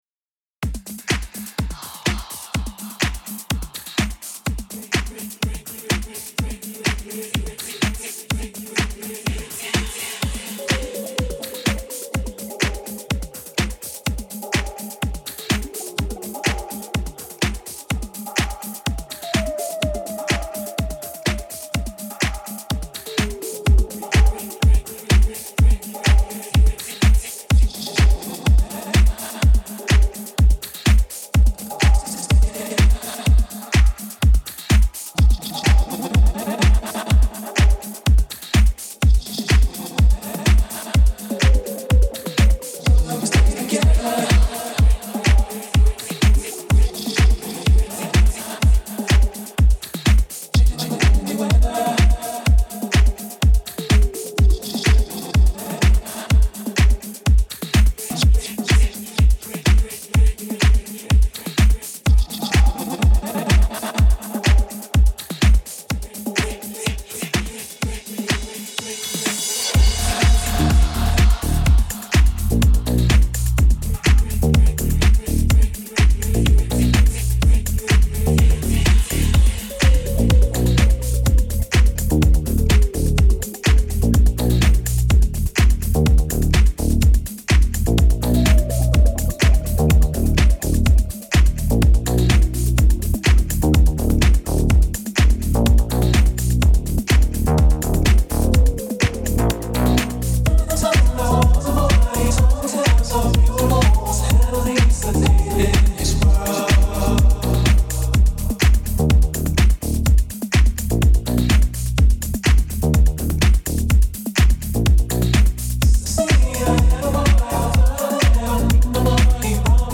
This mix was recorded live
electronic music